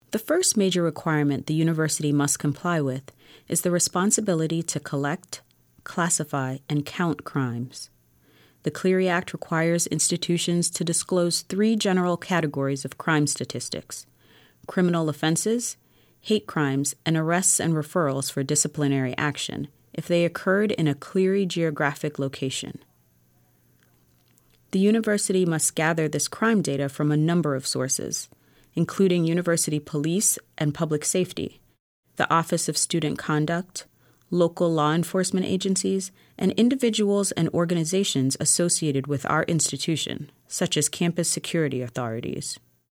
Narration